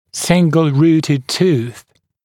[‘sɪŋgl-‘ruːtəd tuːθ][‘сингл-‘ру:тэд ту:с]однокорневой зуб